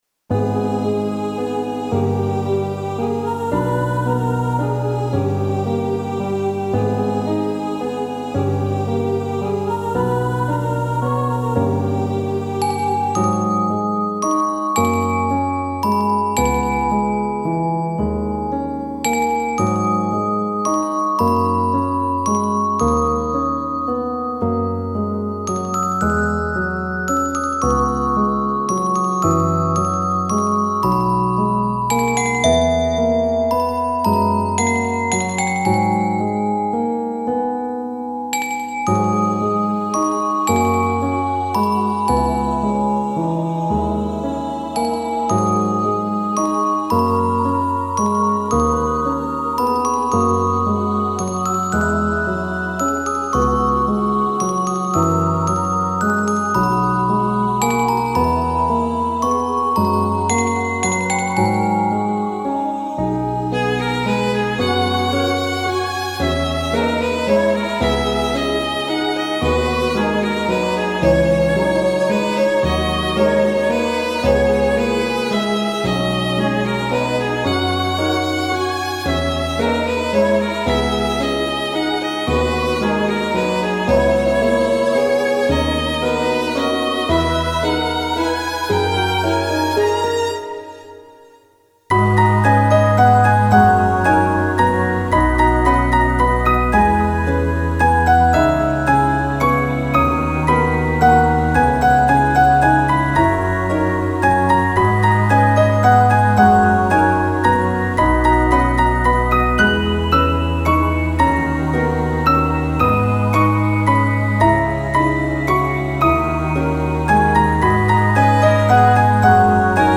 フリーBGM イベントシーン 切ない・悲しい
フェードアウト版のmp3を、こちらのページにて無料で配布しています。